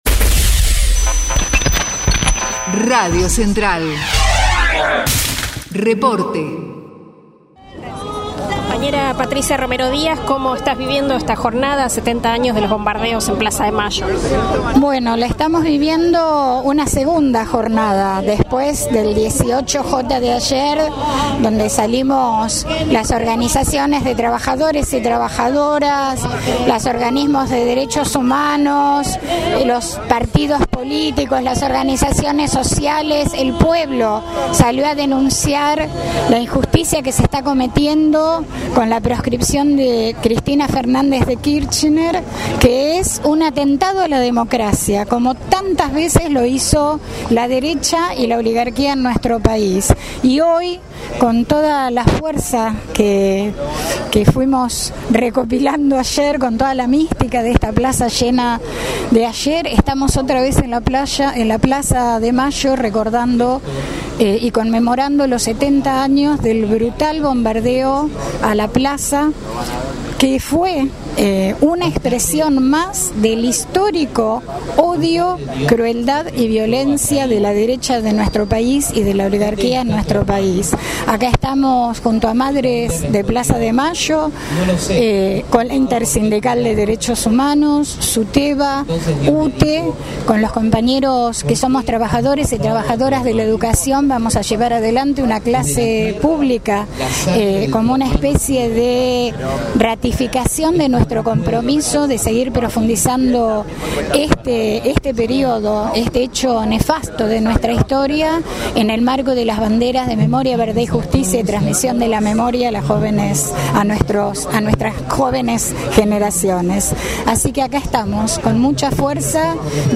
2025_acto_bombardeo_plaza_de_mayo.mp3